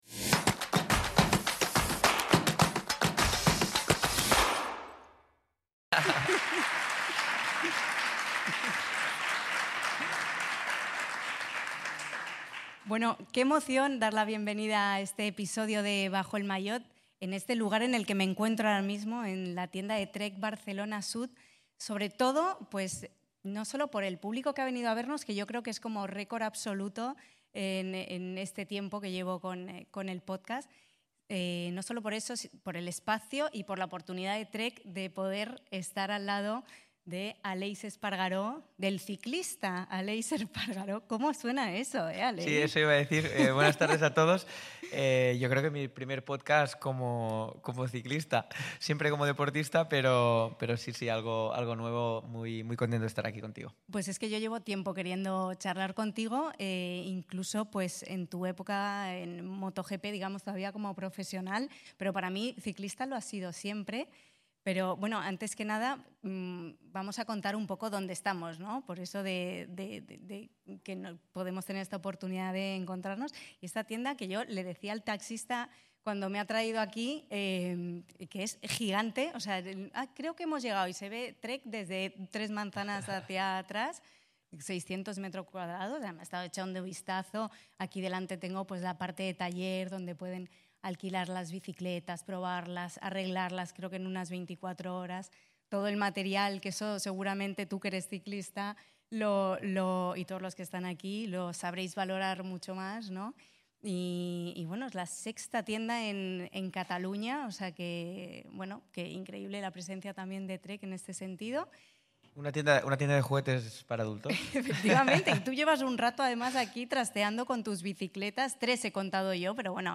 Tras una extensa carrera como piloto de MotoGP, tras su retirada ha tenido la gran fortuna de vivir otro de sus sueños, el de fichar por un equipo profesional como Lidl-Trek. Gracias a Trek Bikes España por abrirnos las puertas de su tienda Trek Barcelona Sud ubicada en la Zona Franca para esta maravillosa charla, a todo su equipo por la acogida y por ponerlo todo tan fácil y a todos los que os acercasteis a vernos.